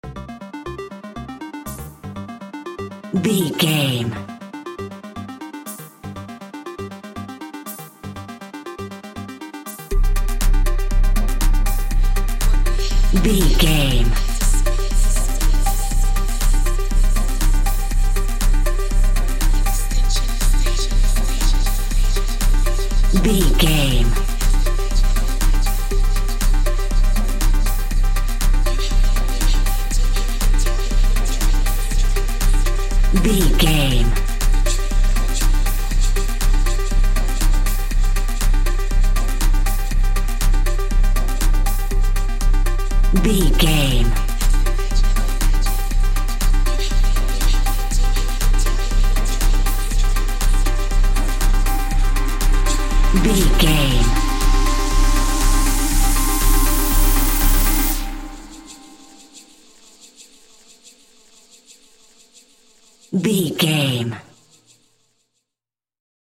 Epic / Action
Fast paced
Aeolian/Minor
Fast
dark
futuristic
groovy
aggressive
synthesiser
drums
vocal
house
techno
trance
synth leads
synth bass
upbeat